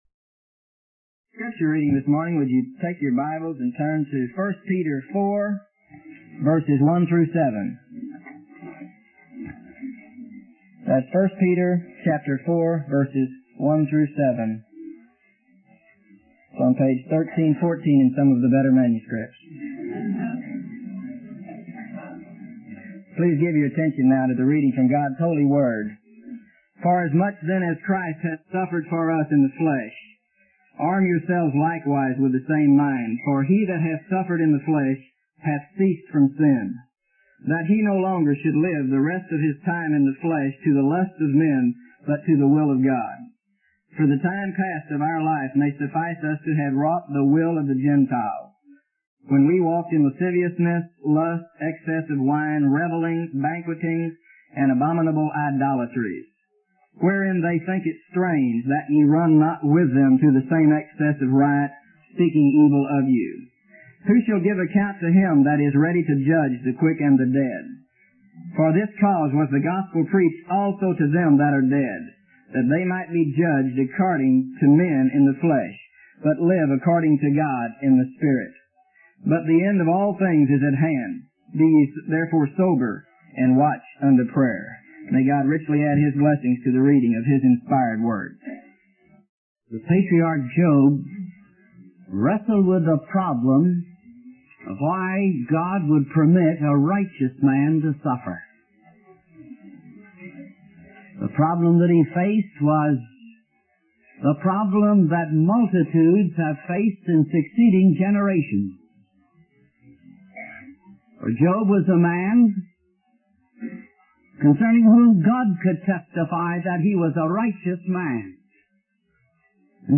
In this sermon, the preacher focuses on the passage from 1 Peter 4:1-7. He emphasizes the importance of suffering in the will of God in order to live in the spirit rather than in the flesh. The preacher compares this to the rigorous discipline and preparation of an athlete before a game.